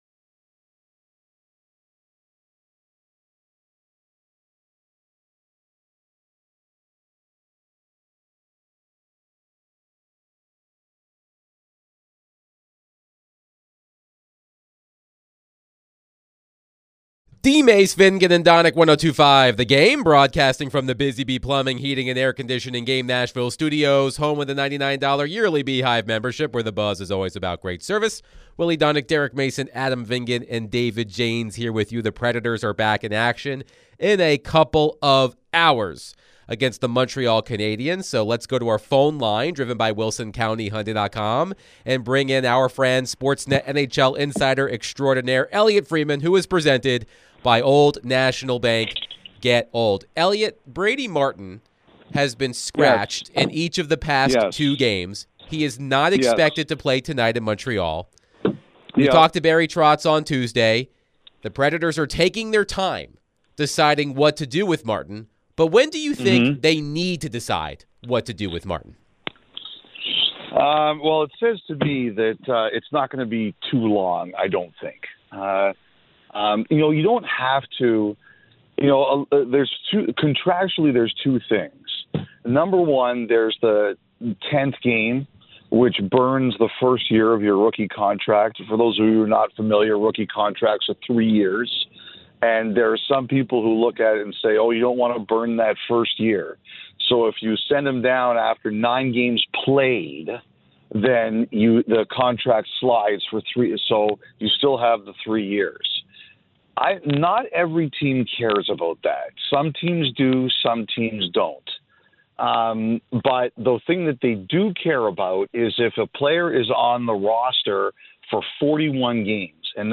NHL Insider Elliotte Friedman joins DVD to discuss all things Nashville Predators and around the NHL.